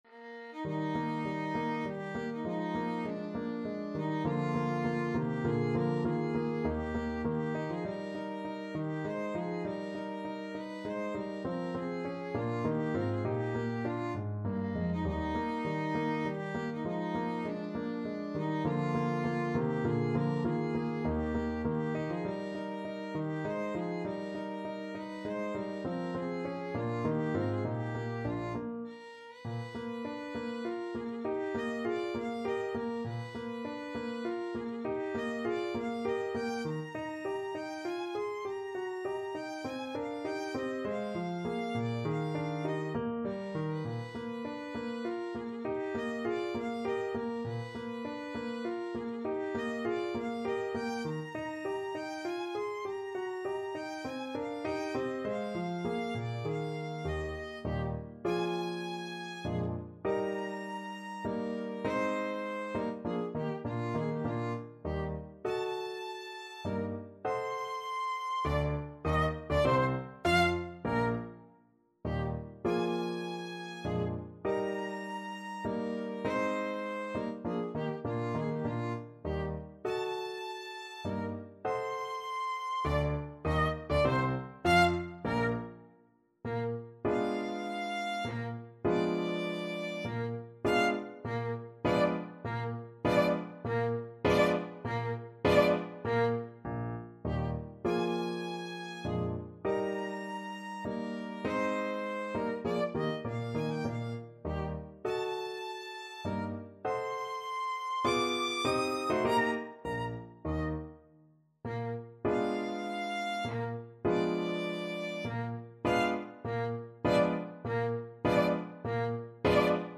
3/4 (View more 3/4 Music)
Menuetto Moderato e grazioso
Classical (View more Classical Violin Music)